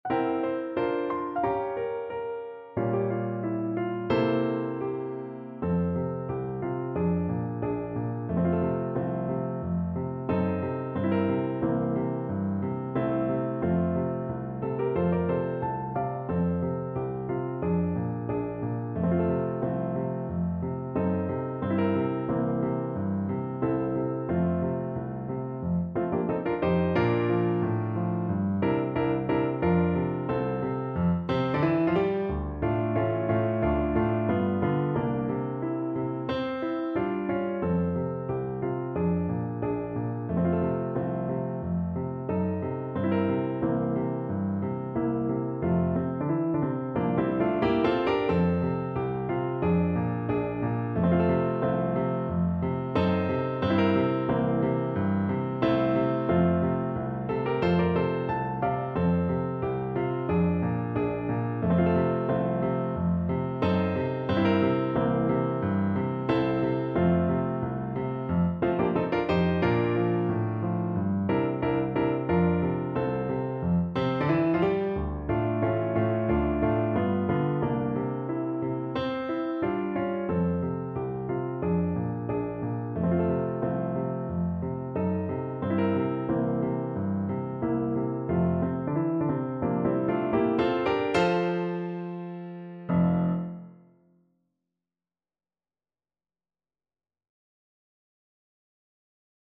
Grazioso =90
2/2 (View more 2/2 Music)